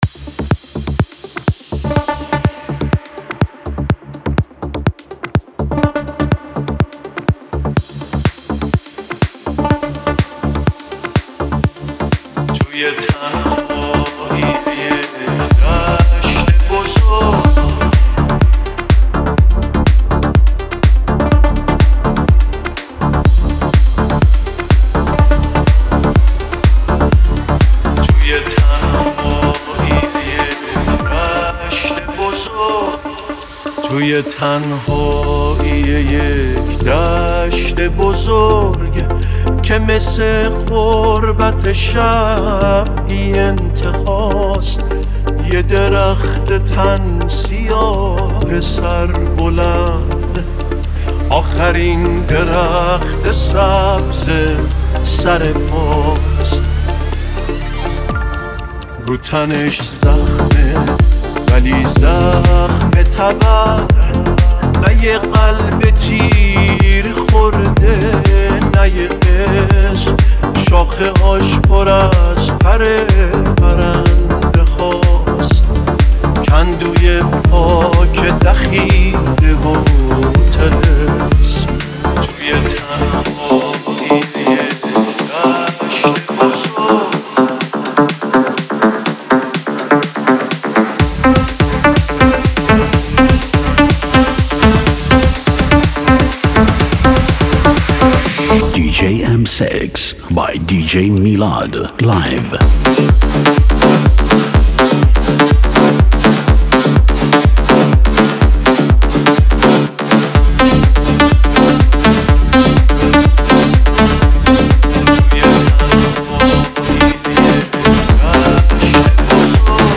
792 بازدید ۲۶ اسفند ۱۴۰۲ ریمیکس , ریمیکس فارسی